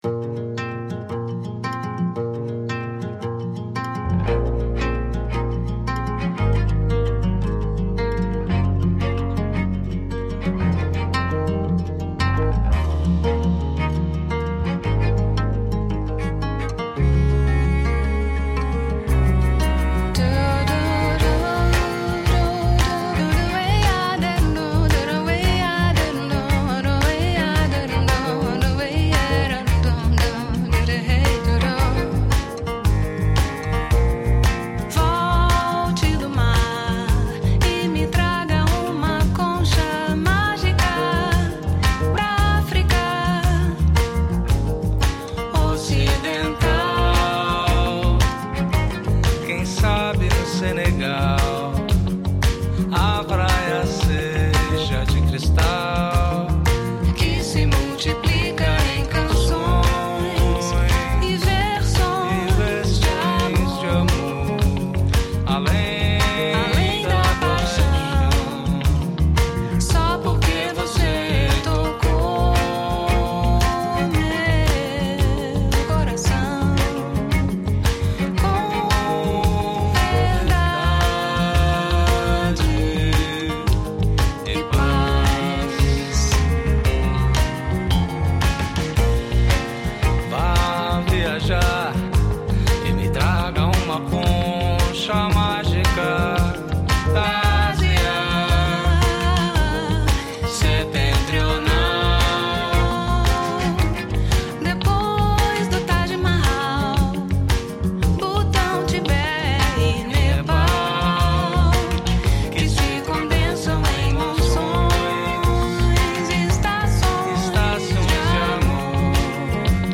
numa levada mais jovem